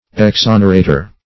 Search Result for " exonerator" : The Collaborative International Dictionary of English v.0.48: Exonerator \Ex*on"er*a`tor\, n. [L., an unloader.]